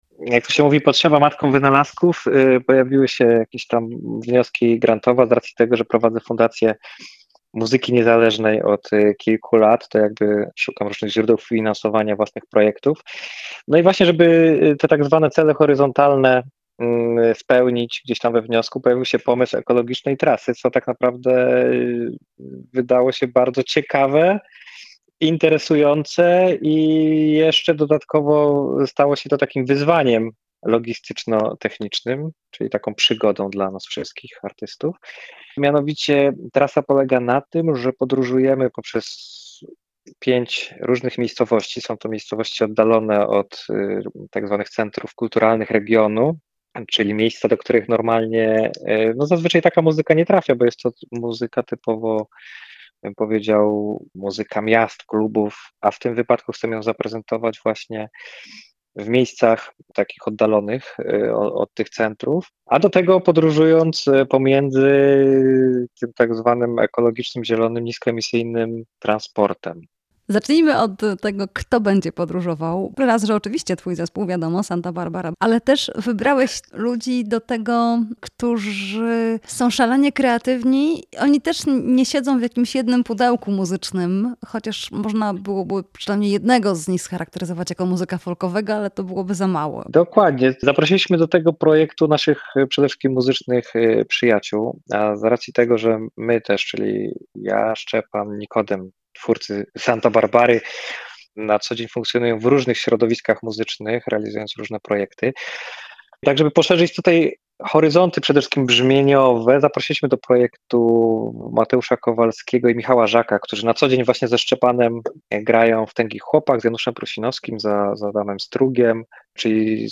Łukasz Pospieszalski opowiedział o tych koncertach słuchaczom Radia Lublin: